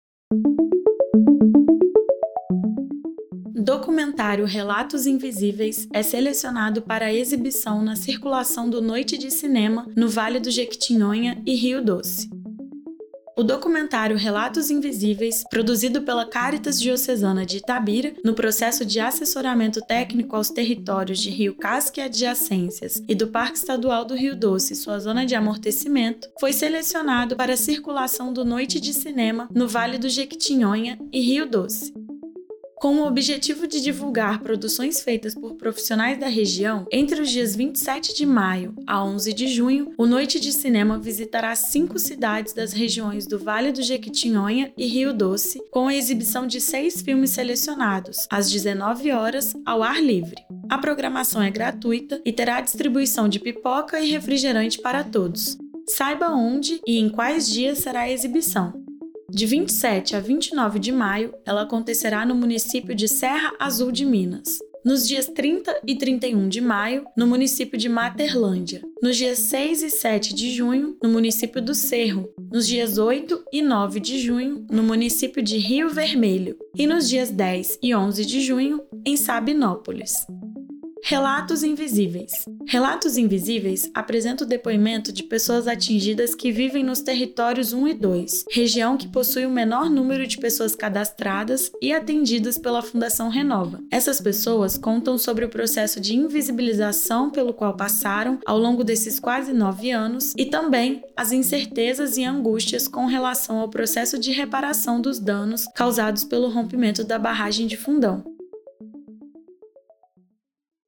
Relatos Invisíveis apresenta o depoimento de pessoas atingidas que vivem nos Territórios 01 e 02, região que possui o menor número de pessoas cadastradas e atendidas pela Fundação Renova. Essas pessoas contam sobre o processo de invisibilização pelo qual passaram ao longo desses quase 9 anos, também, as incertezas e angústias com relação ao processo de reparação dos danos causados pelo rompimento da barragem de Fundão.